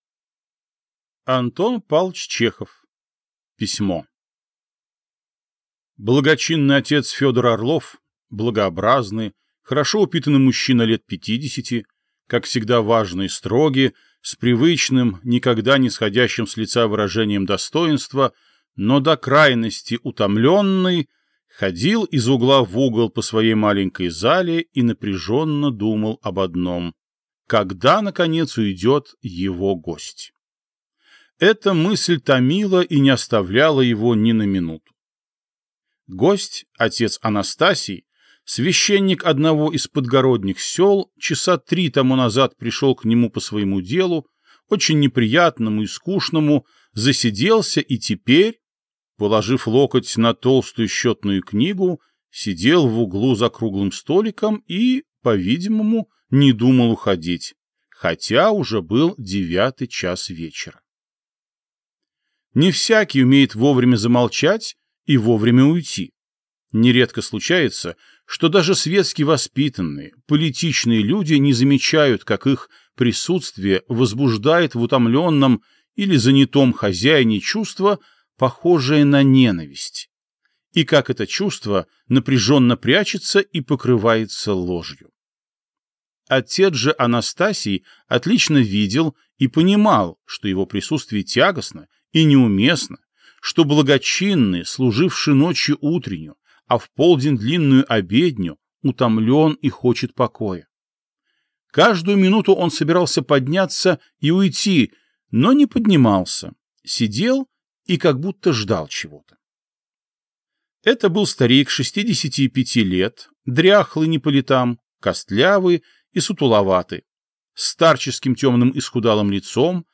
Аудиокнига Письмо | Библиотека аудиокниг